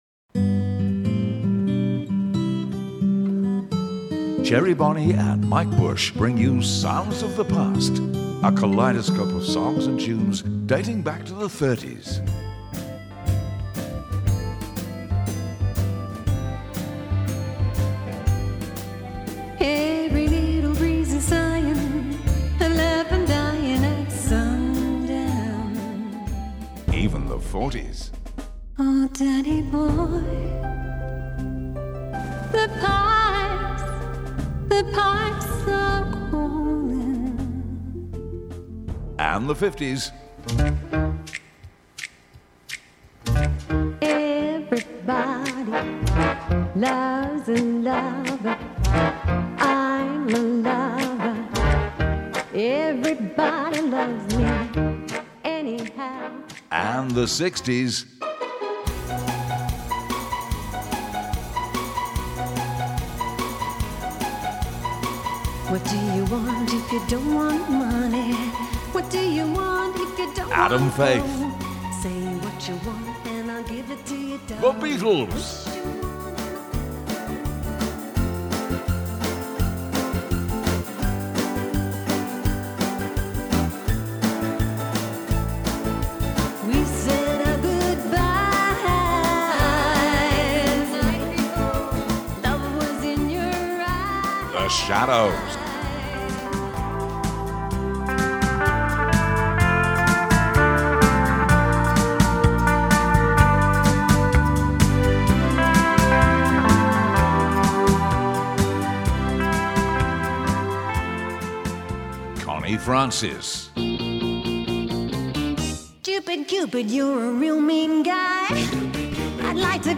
guitarist
playing classic tunes spanning from the 30s to the 80s.